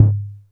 Vintage Tom 01.wav